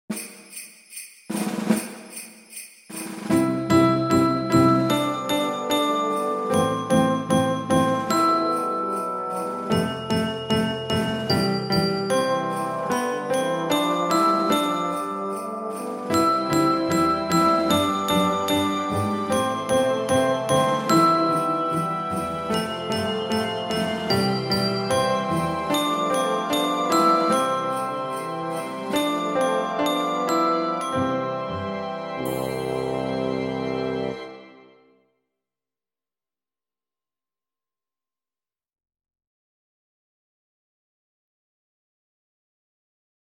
Pr. Accomp